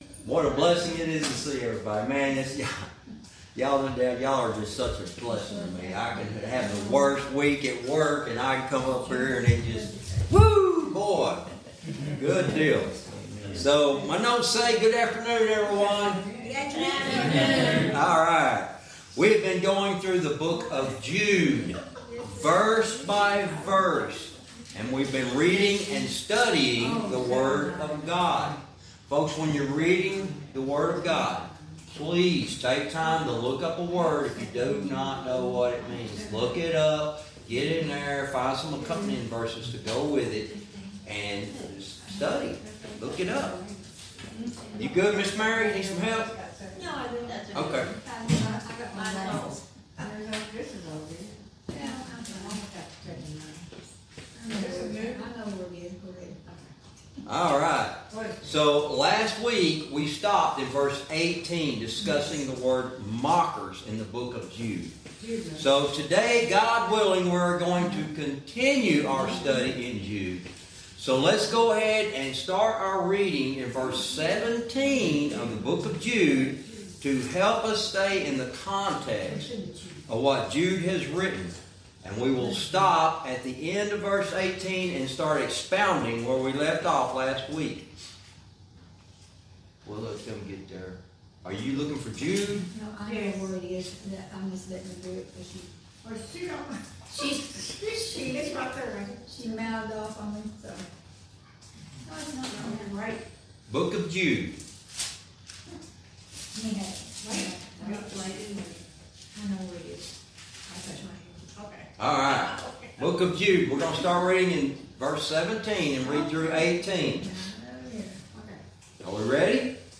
Verse by verse teaching - Jude lesson 79 verse 18